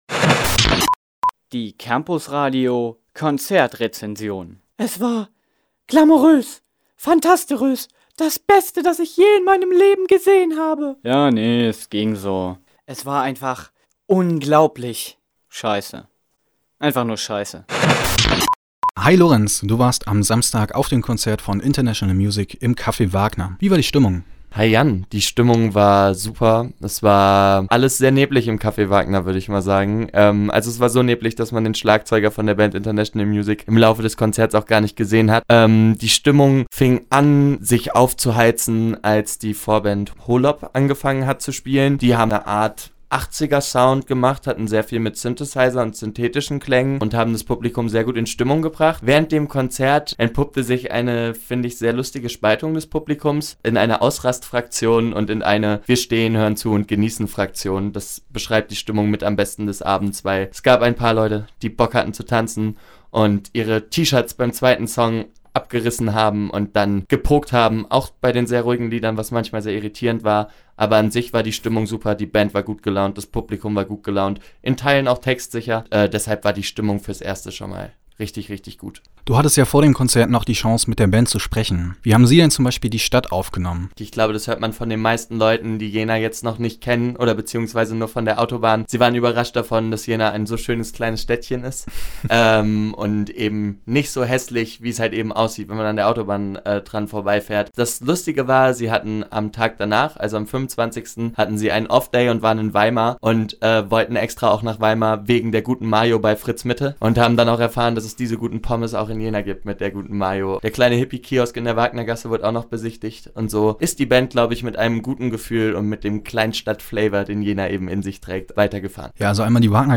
Konzertrezension: International Music – Campusradio Jena